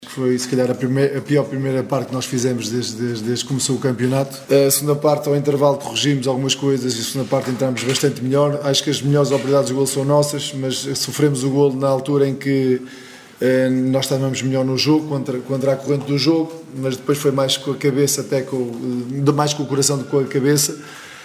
No final da partida, Cesar Peixoto, treinador da equipa gilista, lamentou a má primeira parte da equipa.